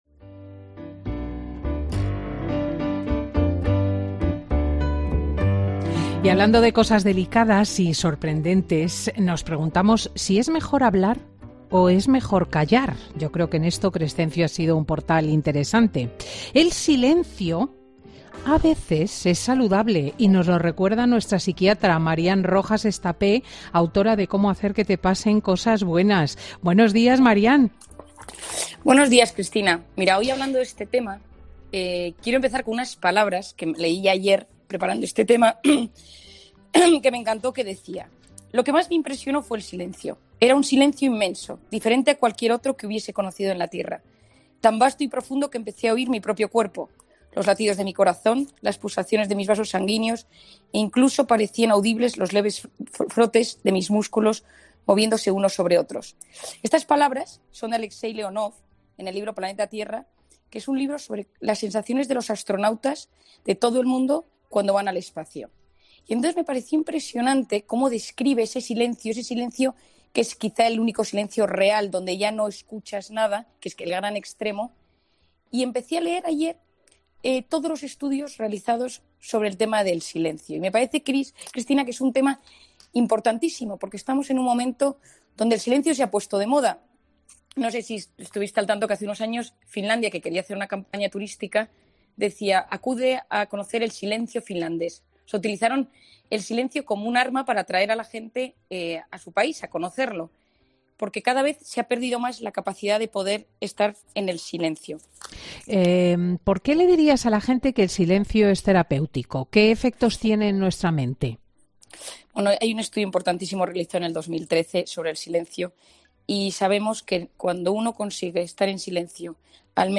La psiquiatra comenta en Fin de Semana con Cristina los beneficios de la soledad meditada